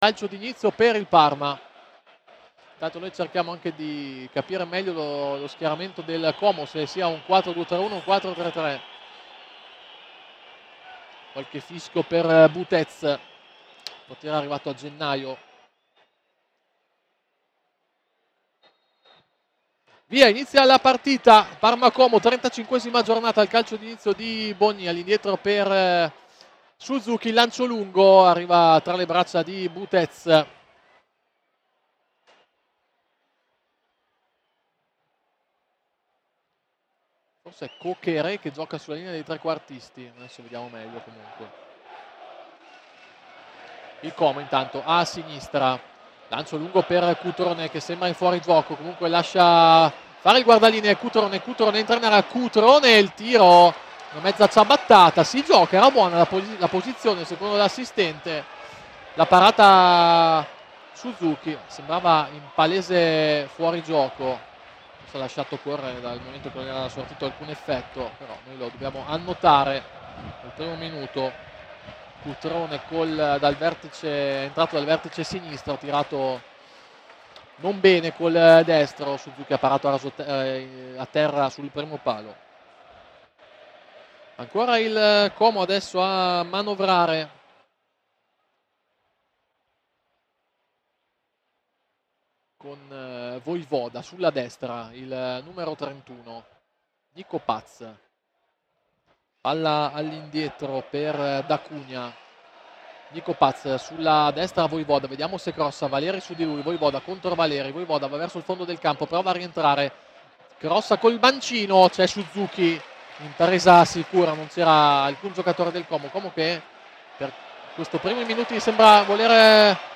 Parma e Como, sfida della 35ª giornata di Serie A. Radiocronaca